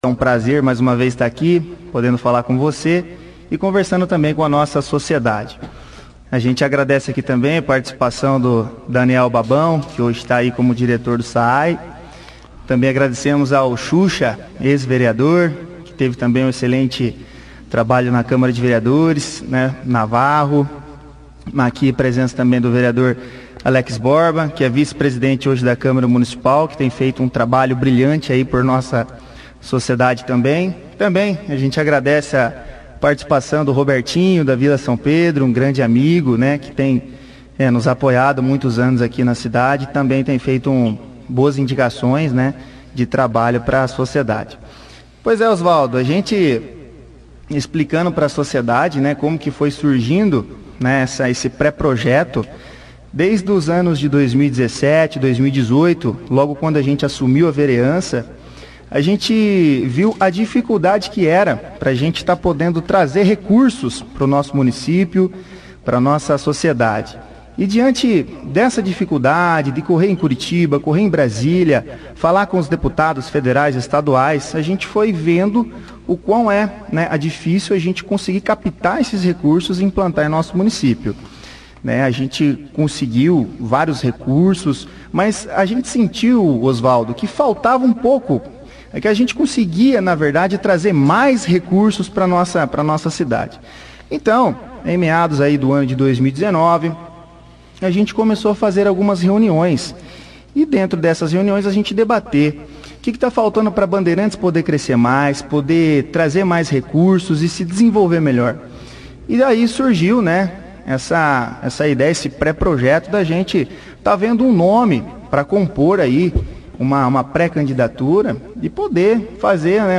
O Vereador e presidente da câmara de vereadores de Bandeirantes, Mano Viera, participou da 2ª edição do jornal Operação Cidade desta sexta-feira, 26/11, falando sobre a sua pré-candidato a deputado estadual, pelo partido PSL.